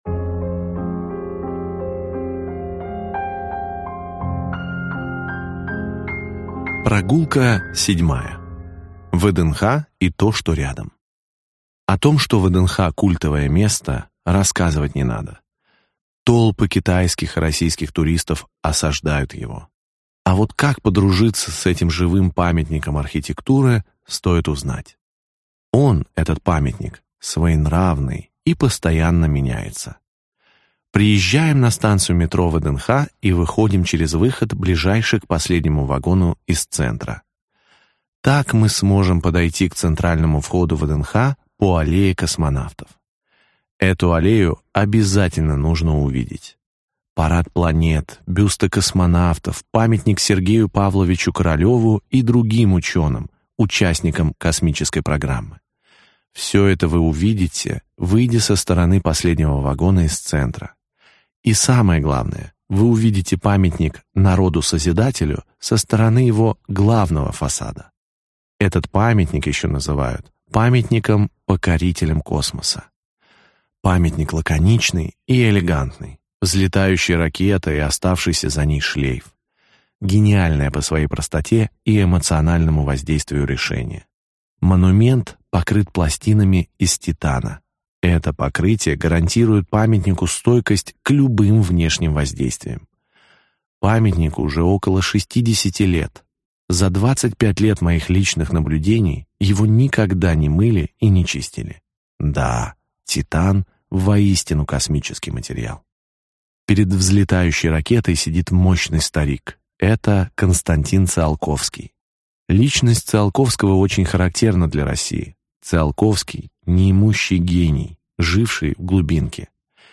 Аудиокнига 8 заповедных мест в Москве, куда можно доехать на метро. Глава 7. ВДНХ, что рядом | Библиотека аудиокниг
Прослушать и бесплатно скачать фрагмент аудиокниги